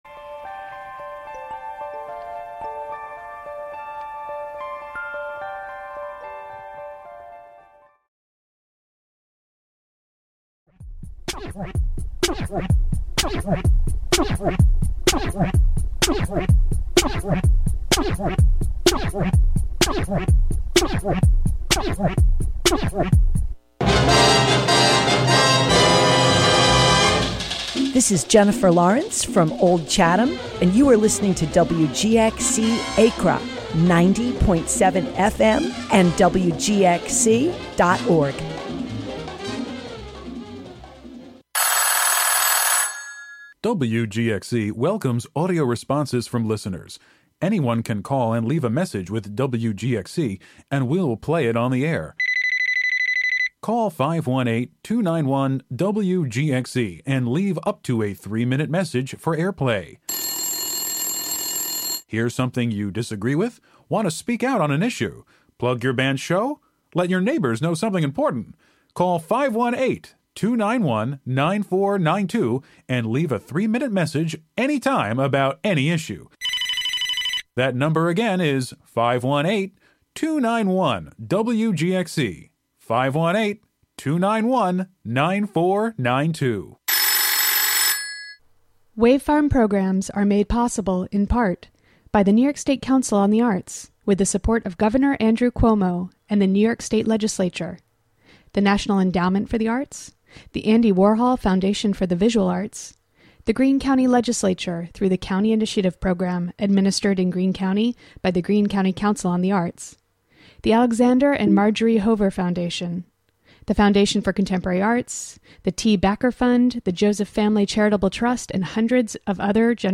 3pm Broadcasting electronic music without borders.